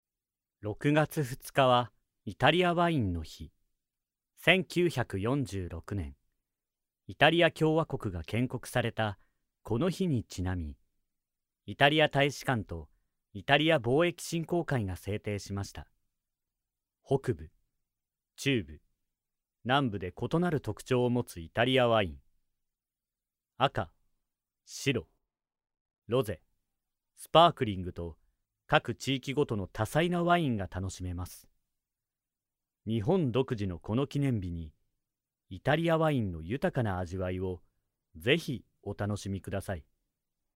声の達人男性ナレーター
明るい
やさしい
さわやか
ゲーム、アニメーション系を用いた動画などへの音声にも親和性が高いタイプの声色と表現です。
ボイスサンプル5（商品紹介）[↓DOWNLOAD]